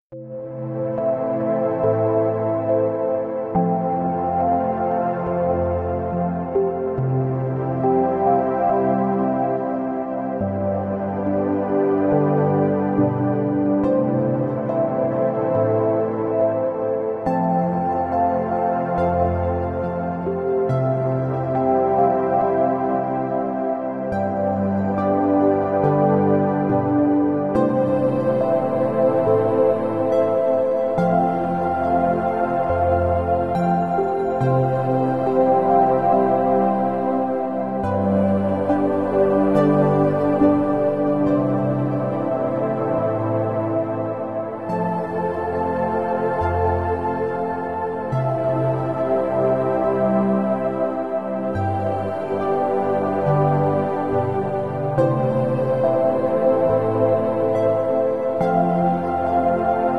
You can actually feel the beat and it sounds better than any other frequency there is.